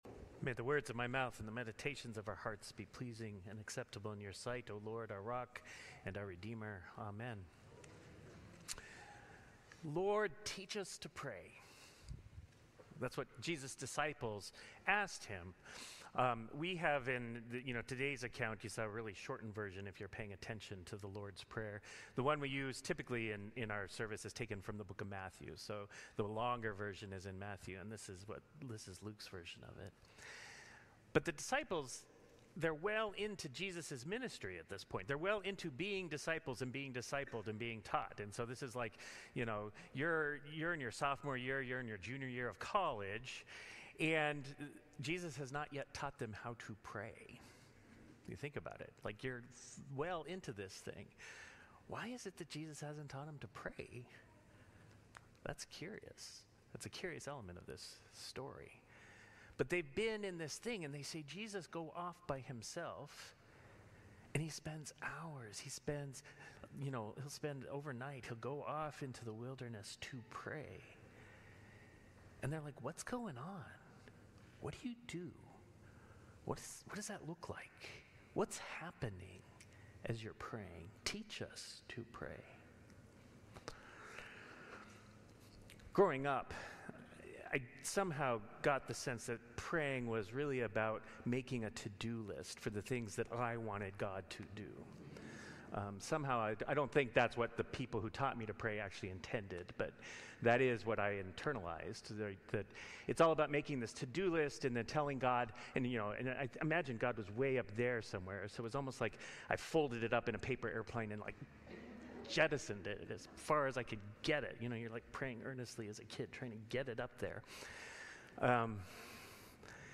Sermons | Grace Episcopal Church
Seventh Sunday of Pentecost